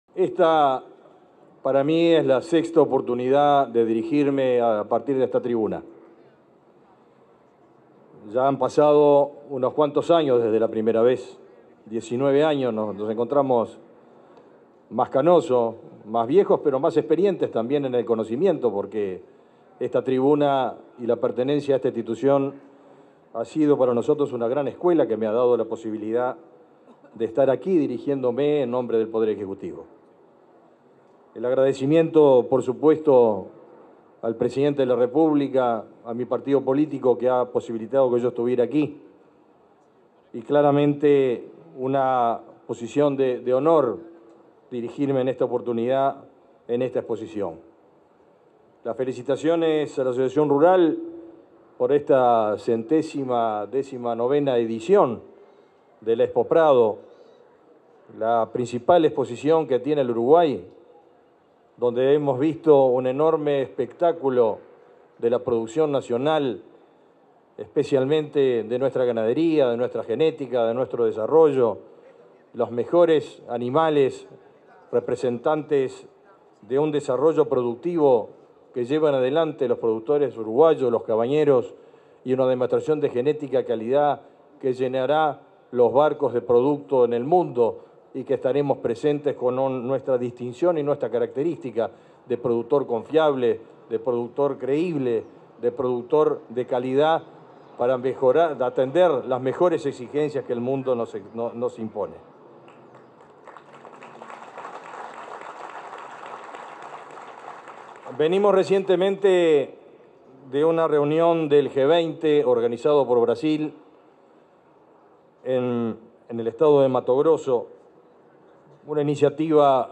Palabras del ministro de Ganadería, Agricultura y Pesca, Fernando Mattos
Palabras del ministro de Ganadería, Agricultura y Pesca, Fernando Mattos 14/09/2024 Compartir Facebook X Copiar enlace WhatsApp LinkedIn En el marco de la ceremonia de cierre de la 119.ª Exposición Internacional de Ganadería, Muestra Agroindustrial y Comercial, Expo Prado 2024, se expresó el ministro de Ganadería, Agricultura y Pesca, Fernando Mattos.